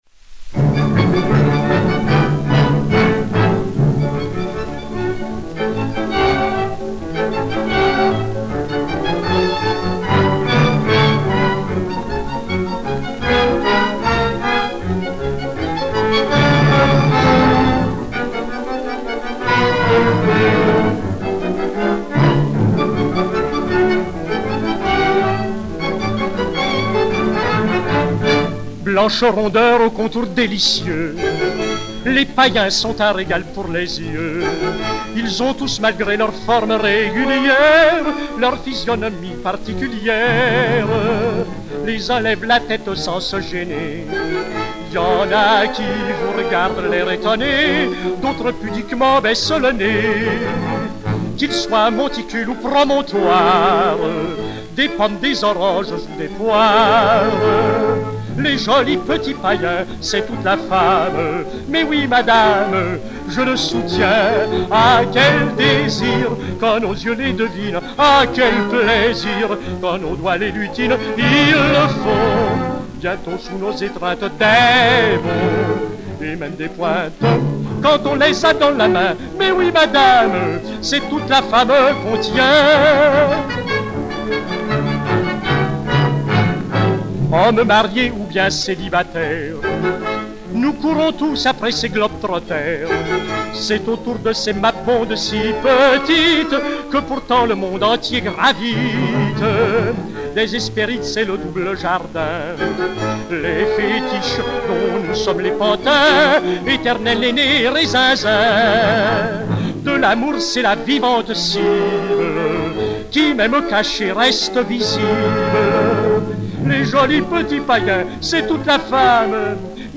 Orchestre